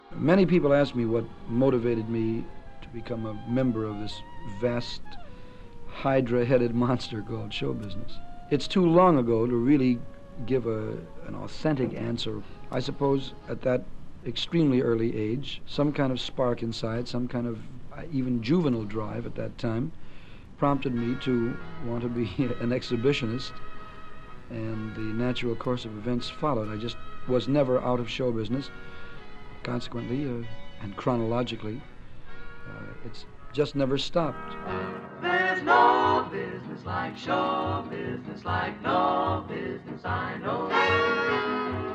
Hear more from legendary jazz man Mel Torme and his son Steve March Torme reflecting on on his life and music in part one of Moira Stuart's Kings of Cool.